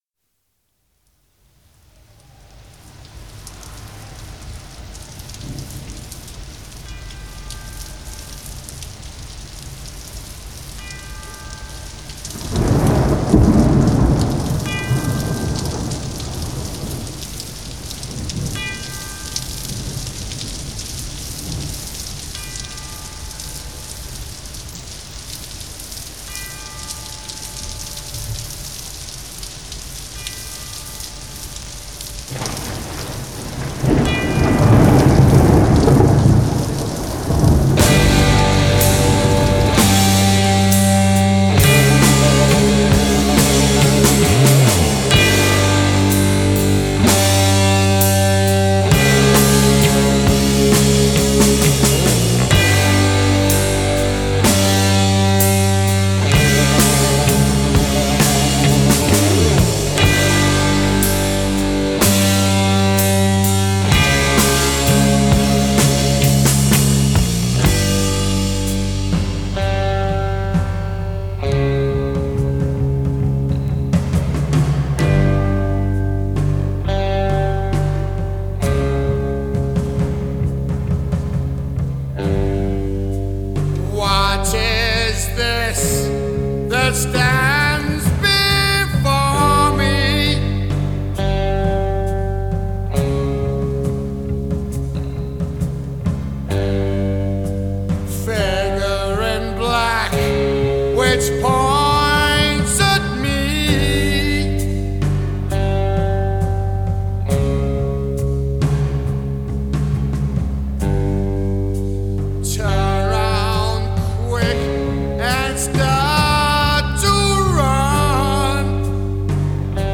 ominous yet mesmerising opening